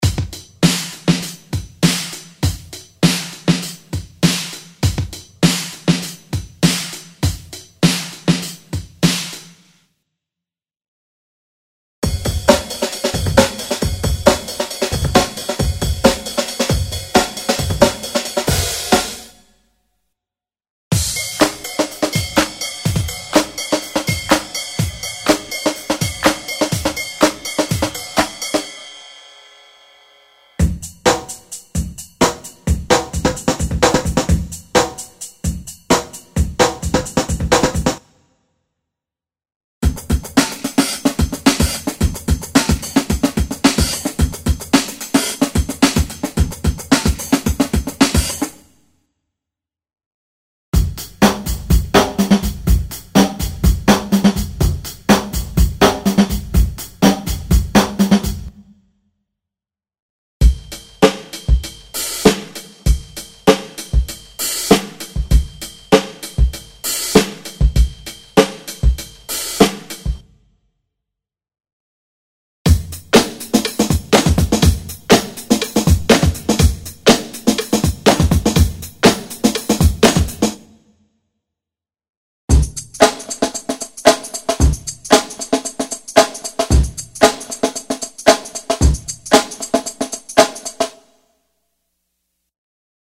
This unique collection of re-engineered breakbeats delivers the authentic drum sound of the 60s — raw, warm, and full of character.
Enhanced through high-end analog gear, these drums hit with the punch and presence your tracks deserve.
All breaks are delivered in uncompressed quality, giving you maximum headroom for your own processing.